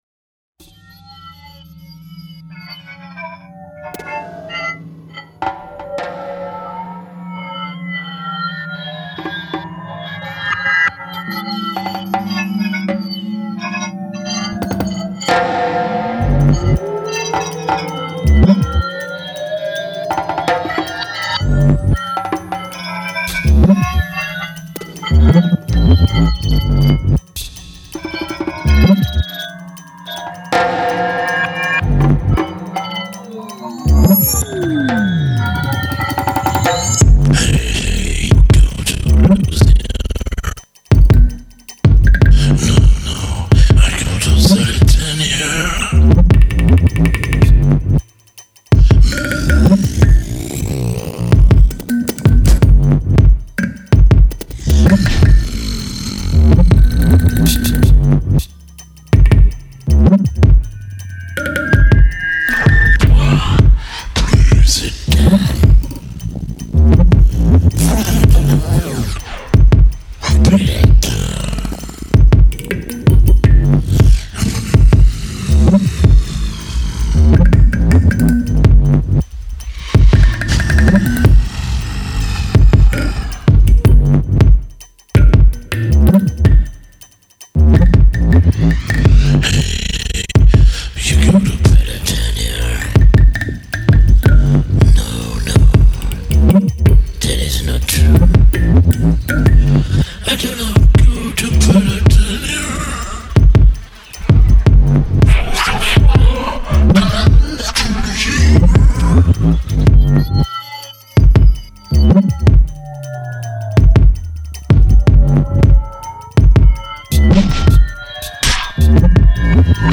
Electro Beats - Percussion & Words